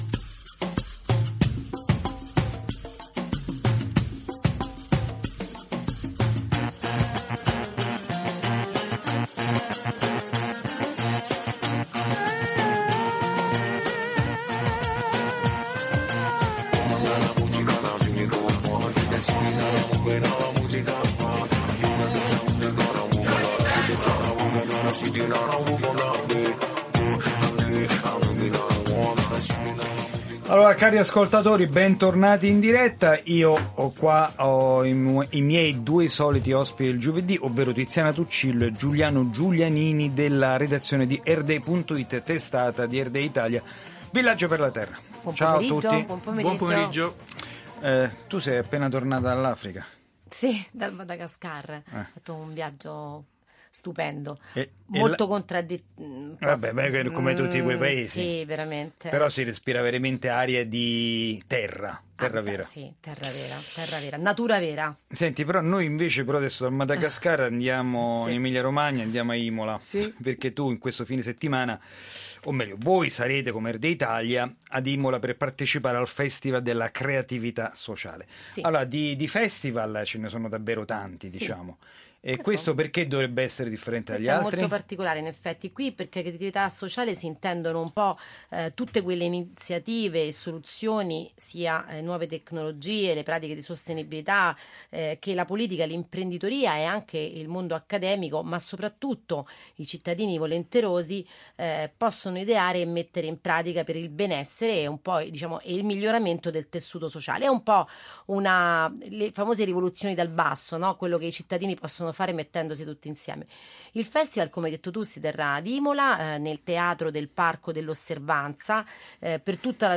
Ecosistema”, la rubrica radiofonica di Earth Day Italia trasmessa da Radio Vaticana, con il contributo di un’intervista